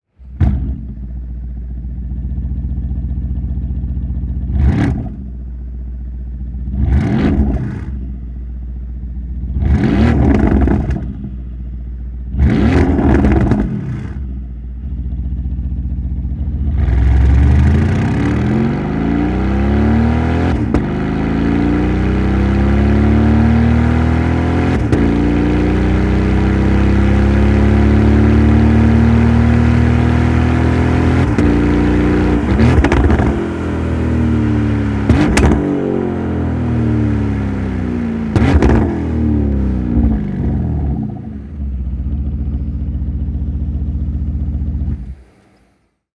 Complete_Exhaust_System_Porsche_Panamera_V8.wav